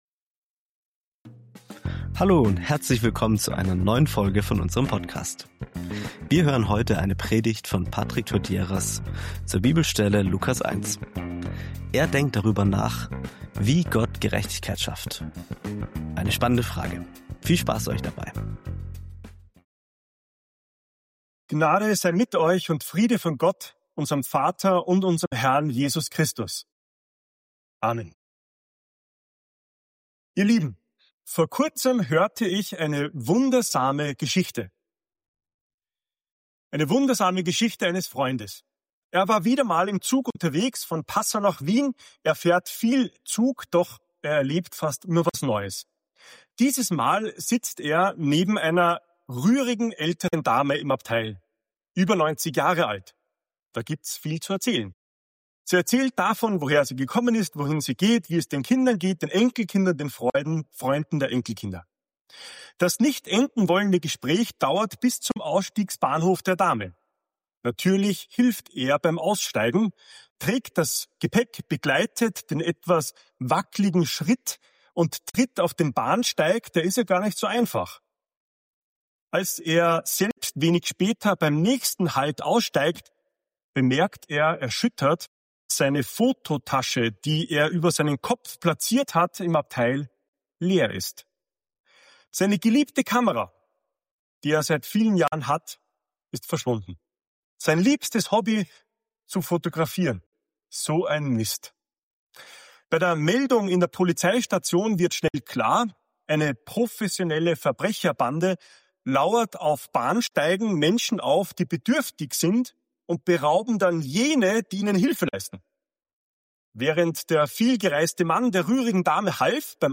In dieser Predigt wird die Geschichte eines Mannes erzählt, der im Zug eine ältere Dame trifft und dabei seine Kamera gestohlen wird.